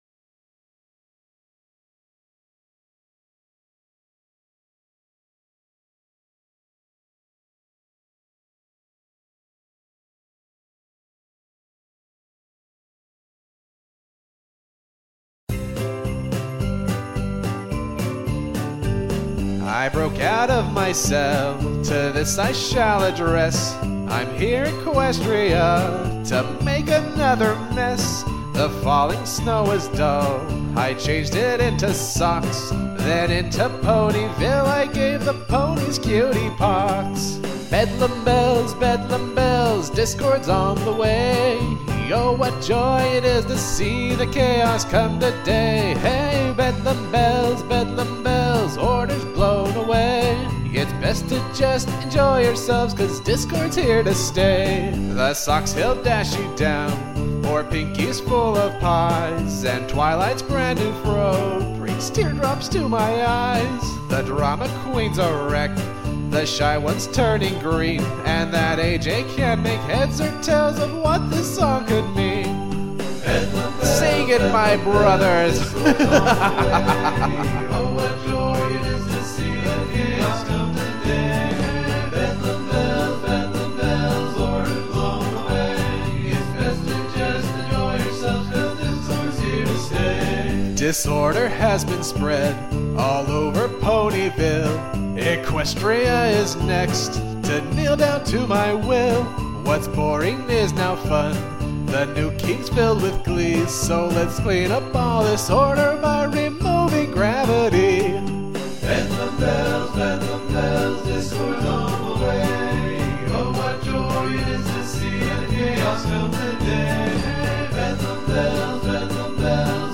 I hadn't, which is why this may sound way off.